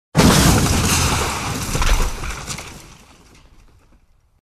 Звуки столкновения
Звук столкновения для монтажа, наезд на препятствие